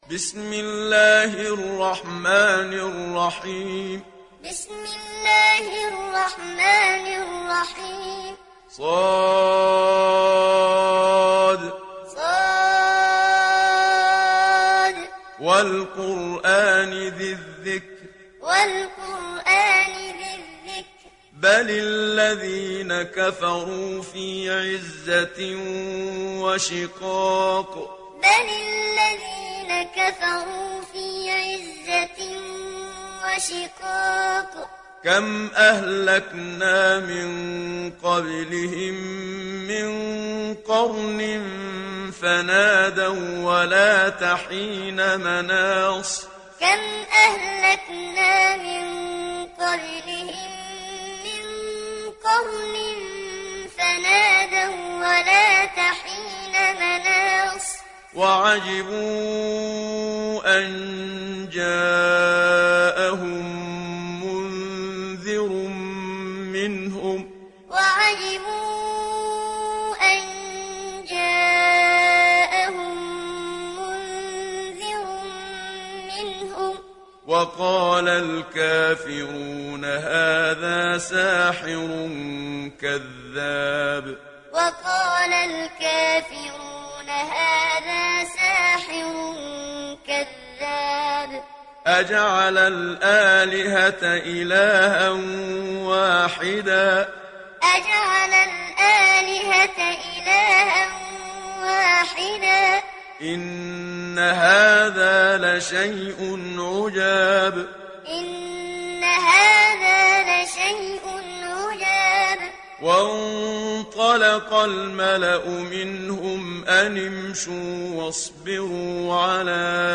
دانلود سوره ص mp3 محمد صديق المنشاوي معلم روایت حفص از عاصم, قرآن را دانلود کنید و گوش کن mp3 ، لینک مستقیم کامل
دانلود سوره ص محمد صديق المنشاوي معلم